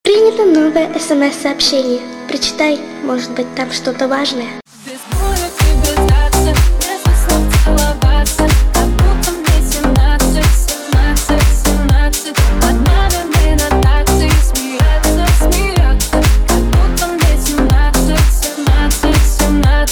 • Качество: 320, Stereo
громкие
remix
женский голос
Club House
детский голос
mash up